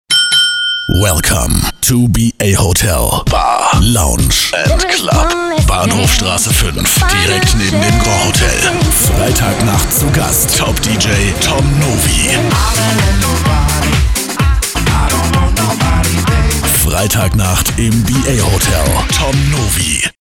Sehr variabel, von seriös über freundlich bis angsteinflößend. Leicht deutscher Akzent im Türkischen.
Türkischer Sprecher.
Tiefe, maskuline Stimme.
Sprechprobe: Sonstiges (Muttersprache):